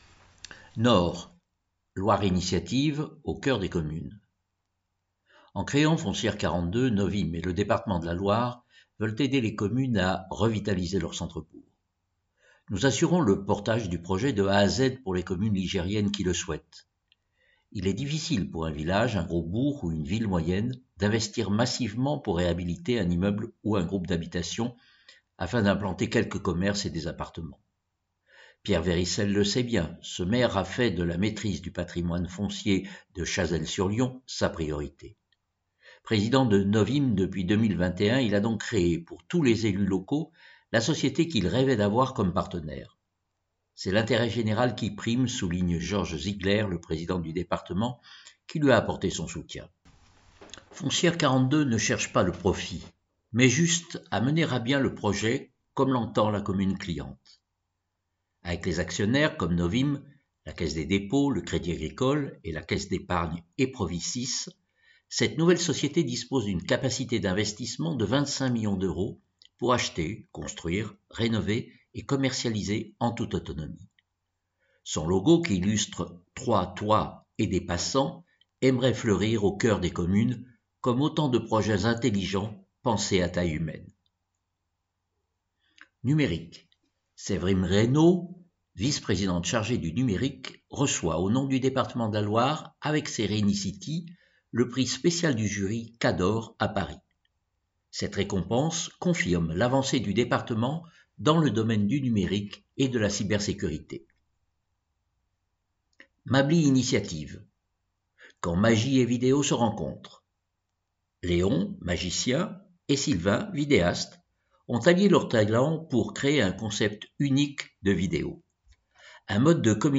Loire Magazine n°155 version sonore